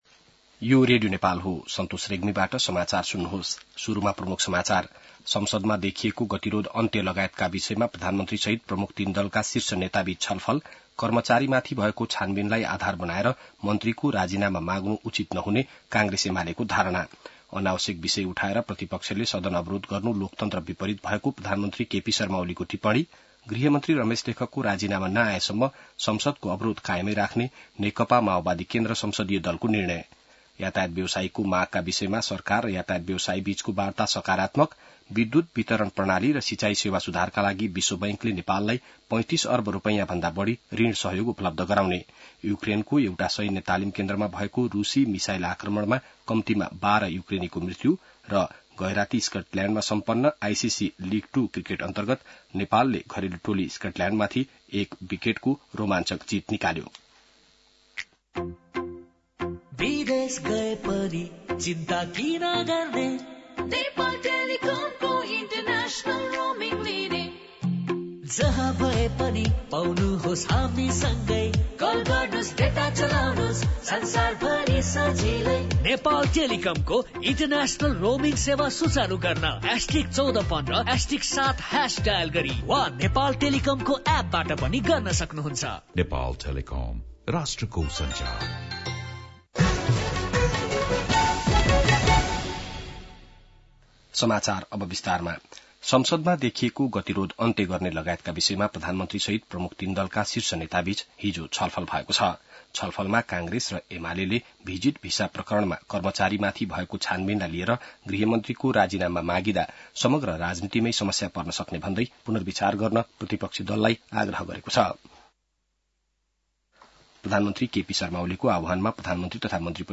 बिहान ७ बजेको नेपाली समाचार : २० जेठ , २०८२